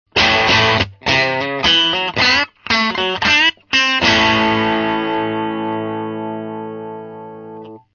(NoEQ,NoEffectで掲載しています）
No.5 MP3 GeorgeL's製のパッチを二本使用。
派手なサウンドキャラクターです。音痩せ感はずいぶん改善されました。
LiveLineよりと比べてもだいぶ派手な感じにきこえます。